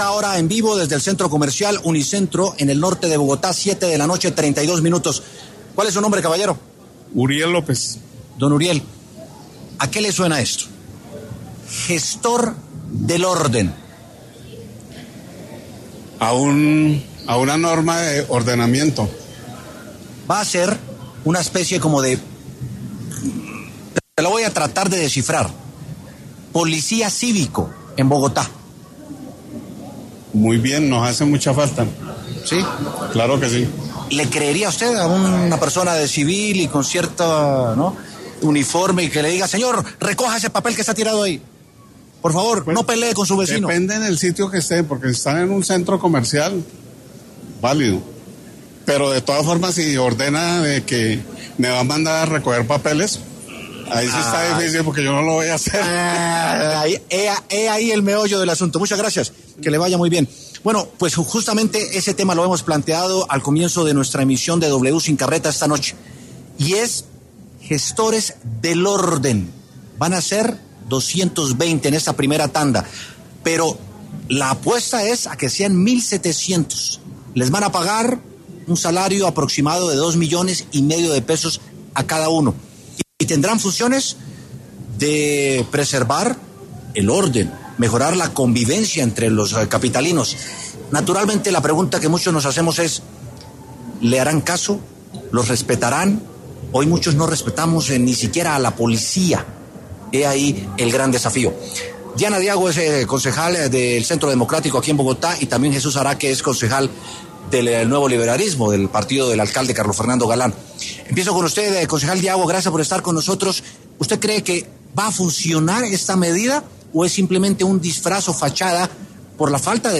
Diana Diago, concejal del Centro Democrático, y Jesús Araque, concejal del Nuevo Liberalismo, debatieron sobre si esta nueva figura, de la que la alcaldía ya lanzó convocatoria, es necesaria.
Este martes, 30 de septiembre, en los micrófonos de W Sin Carreta hablaron los concejales de Bogotá Diana Diago y Jesús Araque, quien se refirió la coyuntura que se ha despertado en Bogotá debido al anuncio de la administración distrital de crear la figura de gestor del orden, para lo cual ya se publicó una convocatoria.